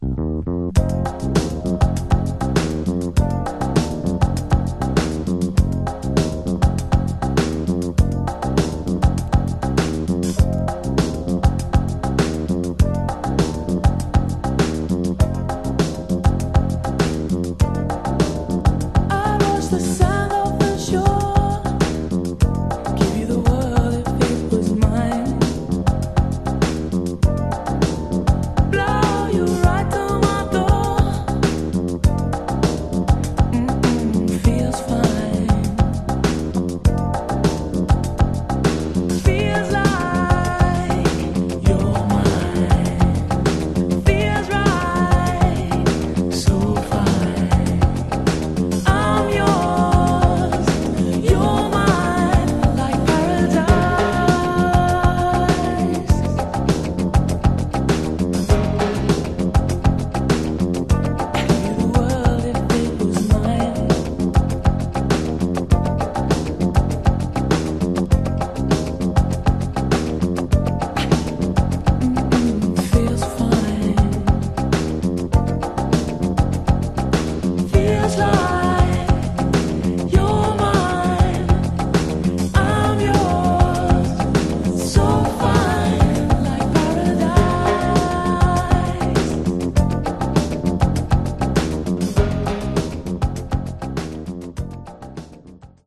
Genre: Other Northern Soul
This terrific dance number